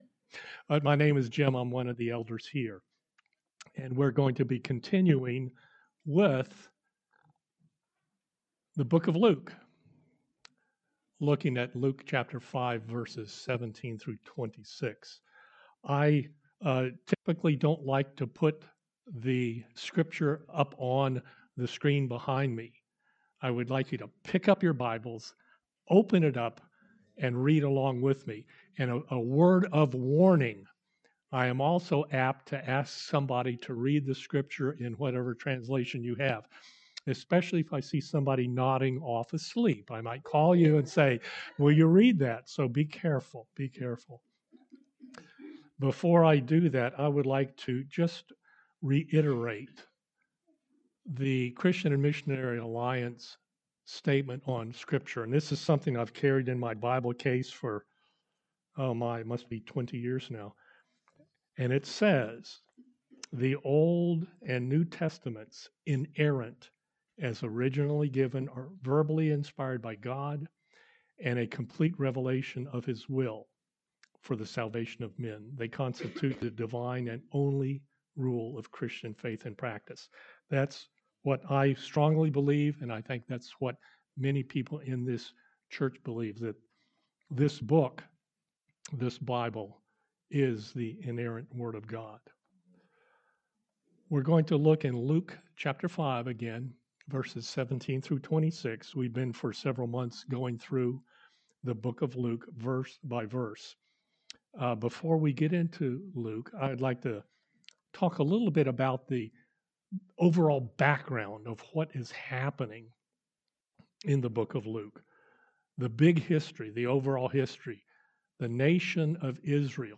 Sermons | Latrobe Alliance Church